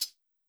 GAR Closed Hat.wav